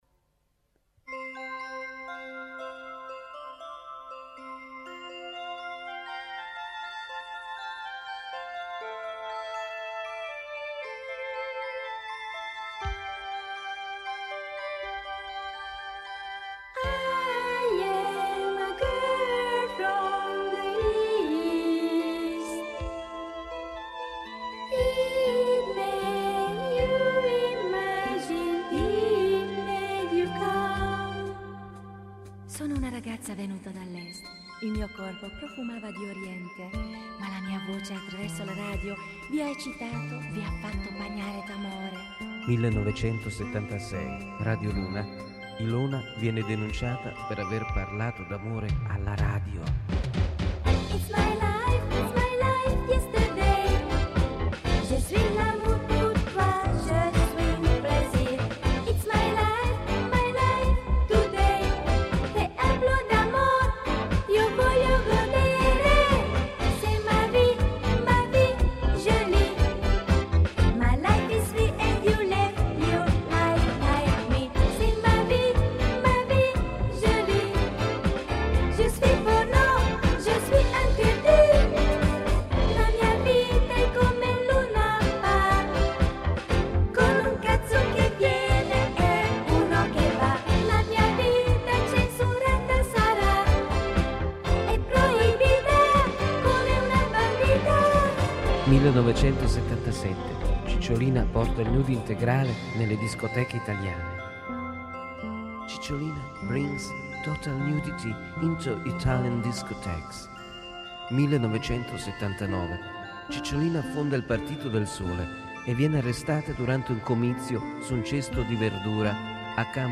موسیقی دهه ۸۰ Disco Music